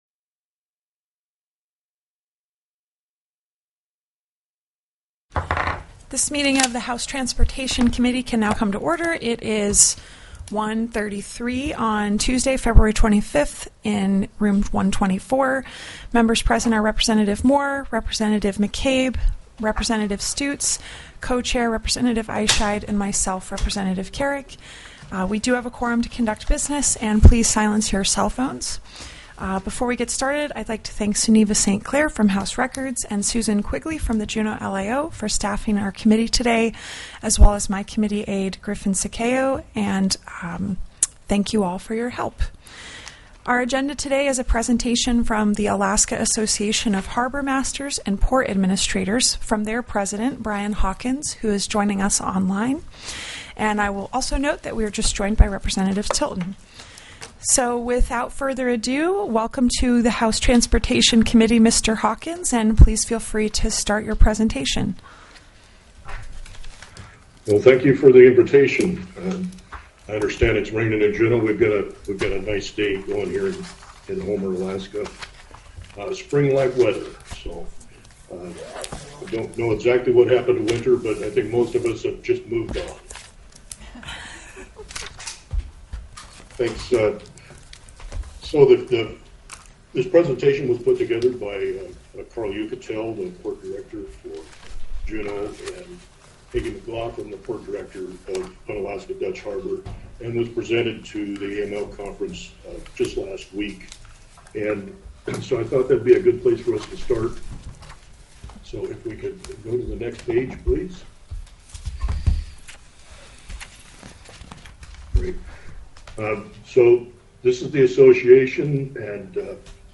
02/25/2025 01:30 PM House TRANSPORTATION
The audio recordings are captured by our records offices as the official record of the meeting and will have more accurate timestamps.